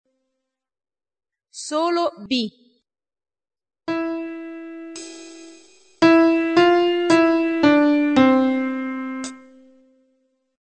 Qualora gli mp3 (di 2ª qualità per non appesantire il sito), di questa pagina, non fossero perfetti nell'ascolto, scriveteci, Vi invieremo sulla vostra casella di posta i file di 1ª qualità, gratuitamente.